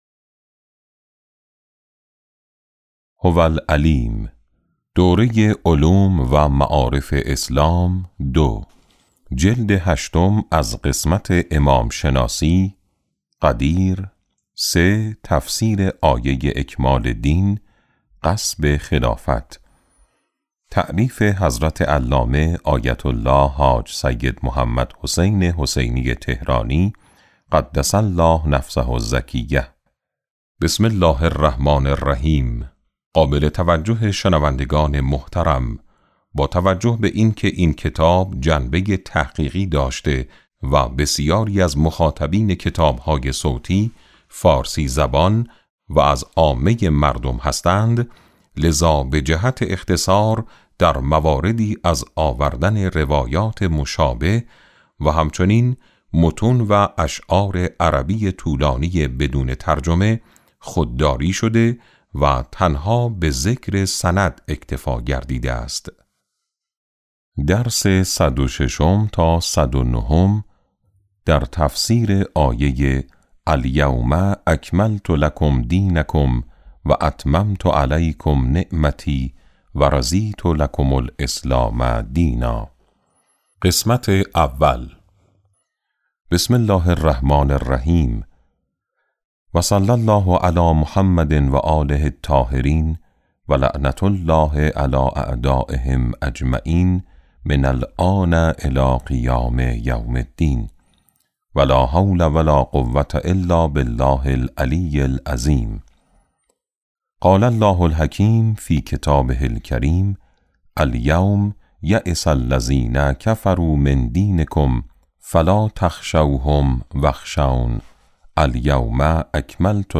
کتاب صوتی امام شناسی ج۸ - جلسه1